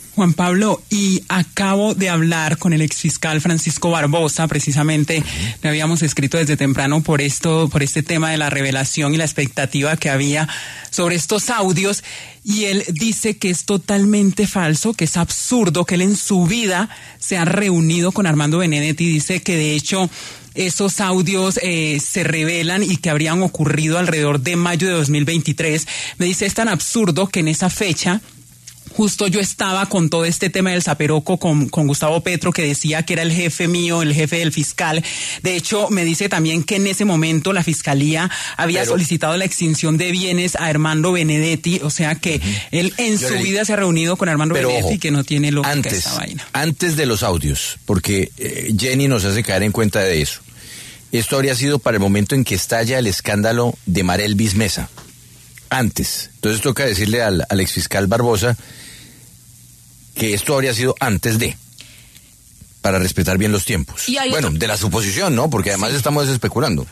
La W habló con el exfiscal Francisco Barbosa tras la revelación del audio que entregó la canciller Laura Sarabia, en medio de sus declaraciones como testigo en las investigaciones por presuntas irregularidades en la financiación de la campaña ‘Petro presidente 2022-2026’.